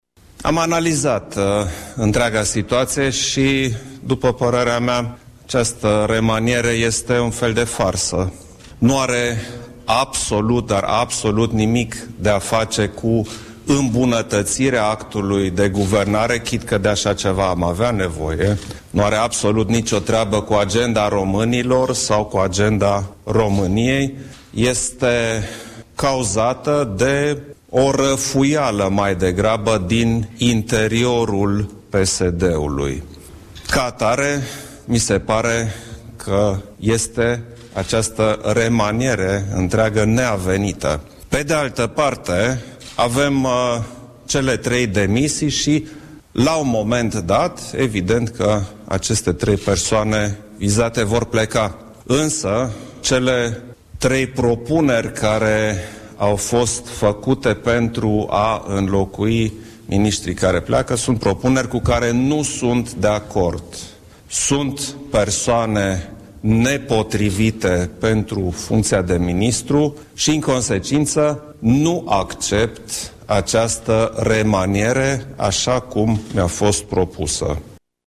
Într-o declarație de presă susținută la Palatul Cotroceni, președintele susține că remanierea guvernamentală propusă de PSD este „un fel de farsă” :